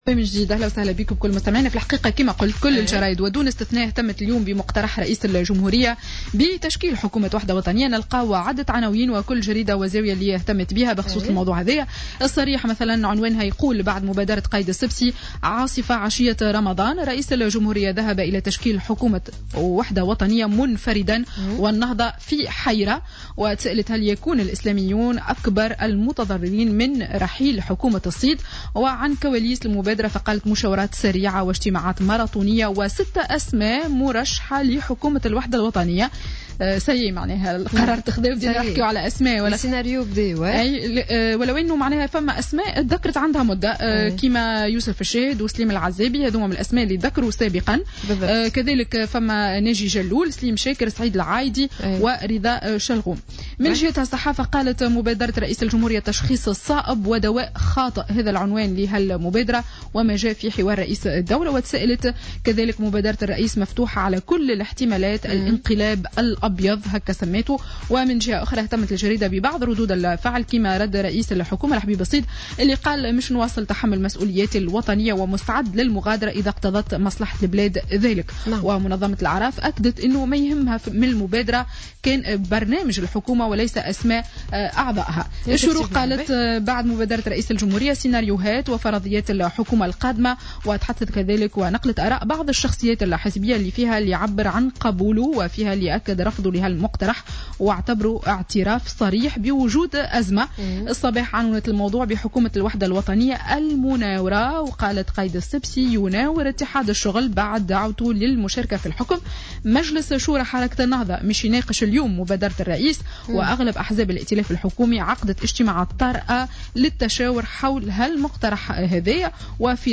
Revue de presse du samedi 4 Juin 2016